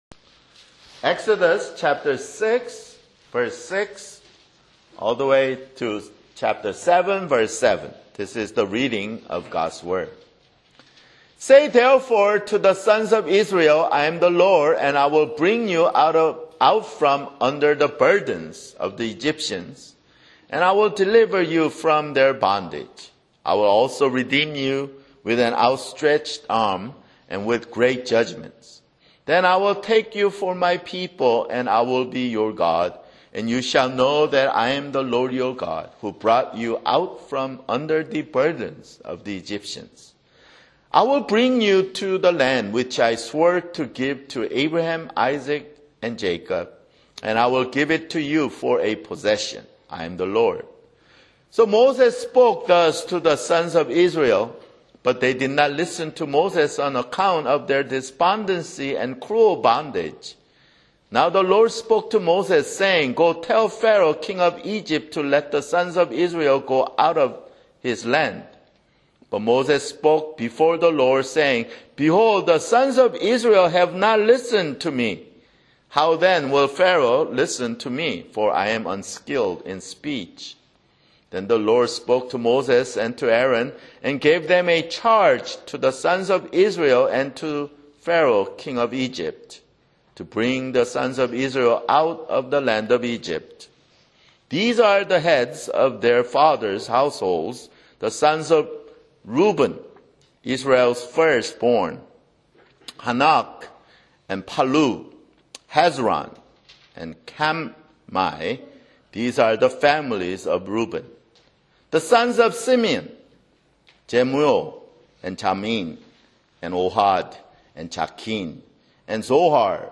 [Sermon] Exodus (17)